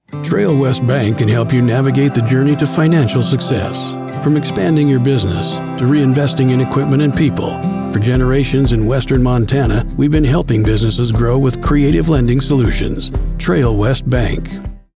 • Clean, broadcast‑ready audio from a professional studio
Just a real voice with real grit.
Commercial Voice Over Demos